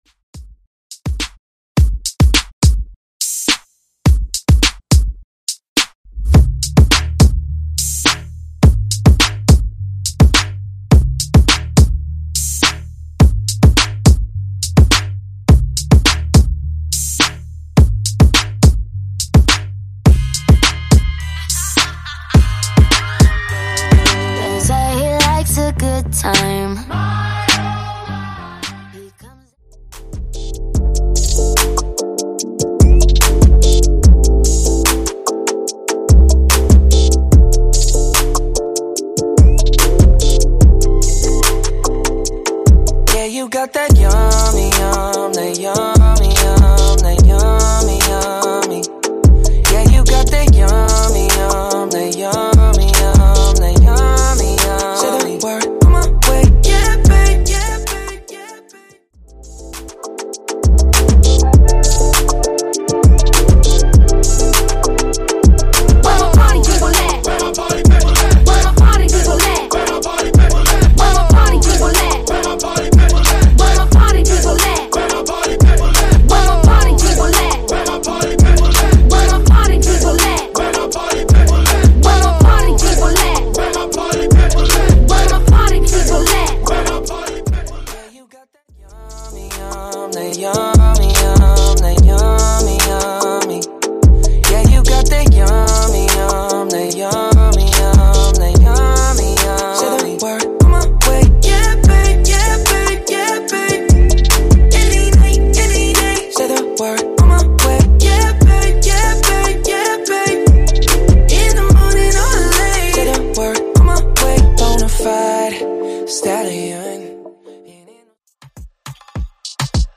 BOOTLEG , MASHUPS , TOP40 Version